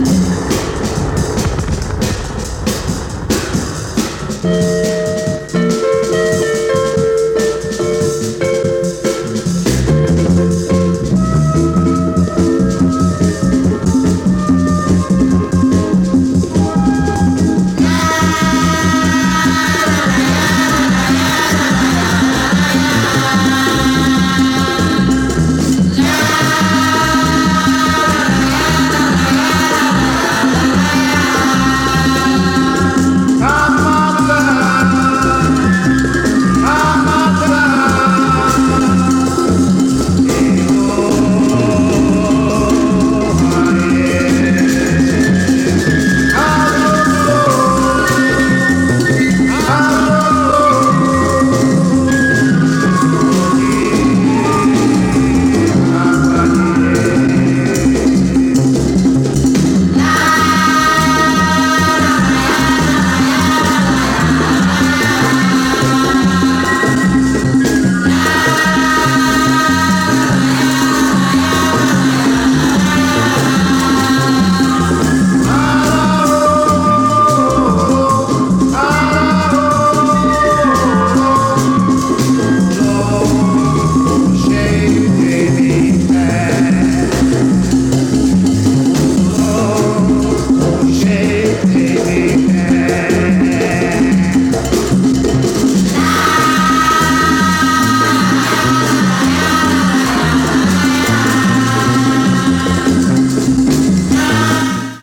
大傑作！圧倒的なフリーク・アウト感を漂わせる、ブラジル産アフロ・サイケの最高峰！